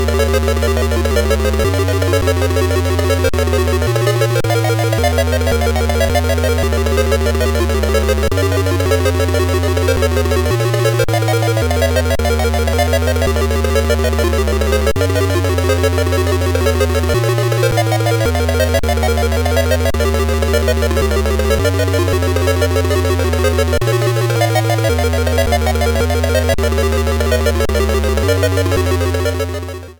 Self-captured from the Sharp X1 version